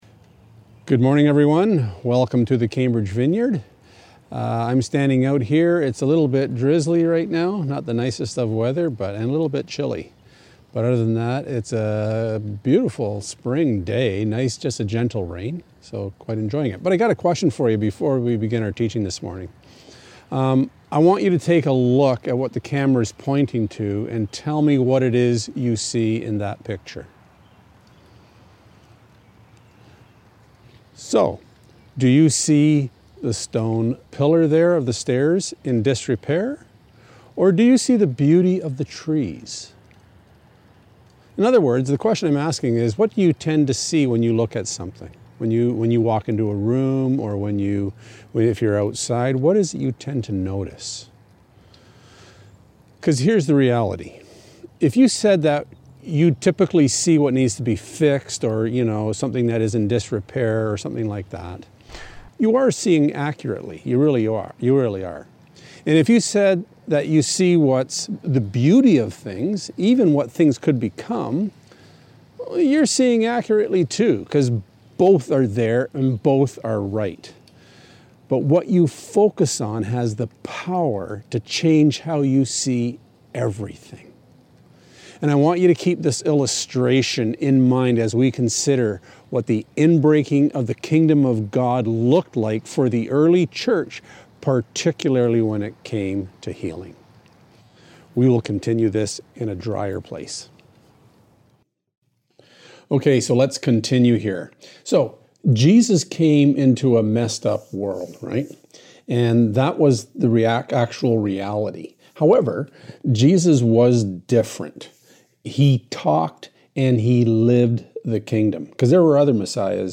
2020 By My Spirit Preacher